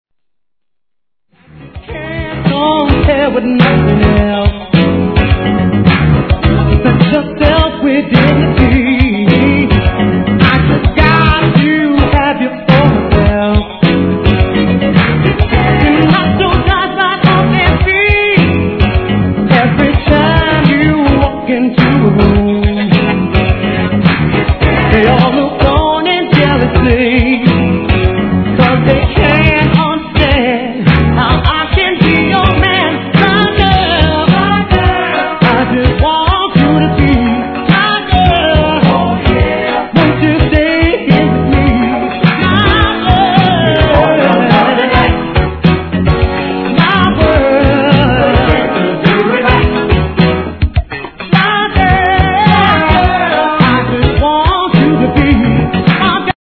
SOUL/FUNK/etc... 店舗 ただいま品切れ中です お気に入りに追加 '84年、モダン・ダンクラ！